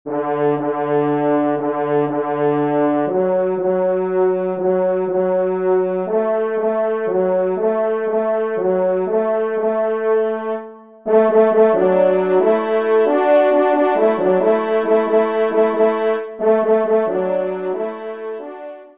Pupitre 2°Trompe